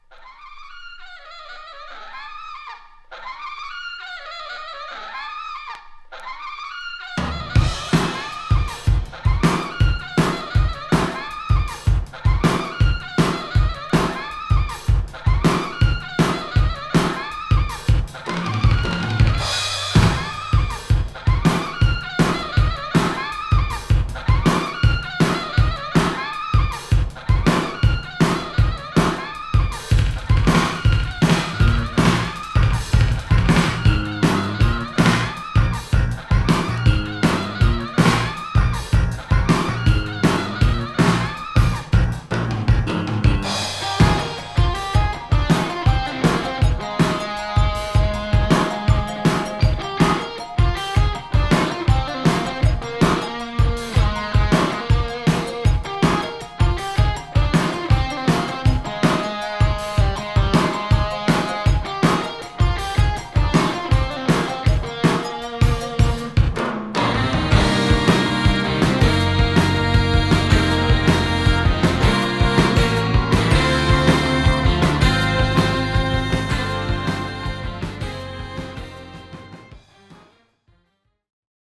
JAPANESE NEW WAVE FUSION！
ブレイキンなニューウェイヴ・フュージョン